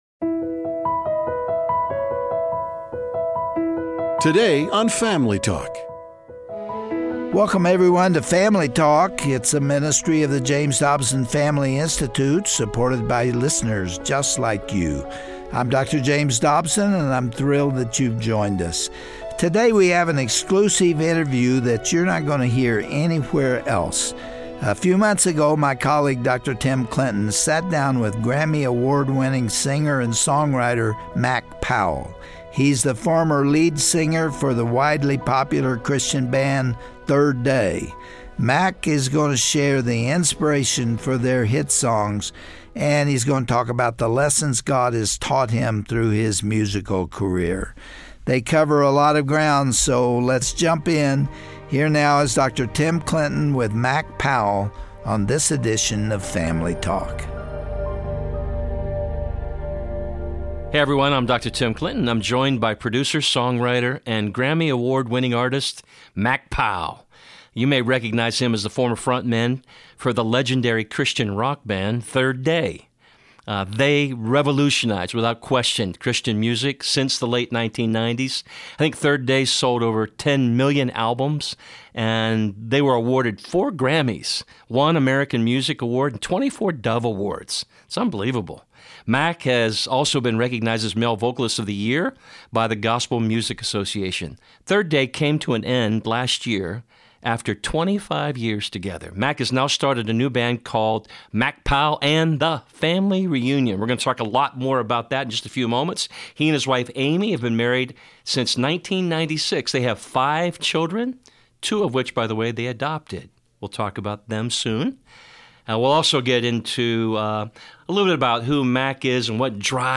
Show Me Your Glory: An Interview with Mac Powell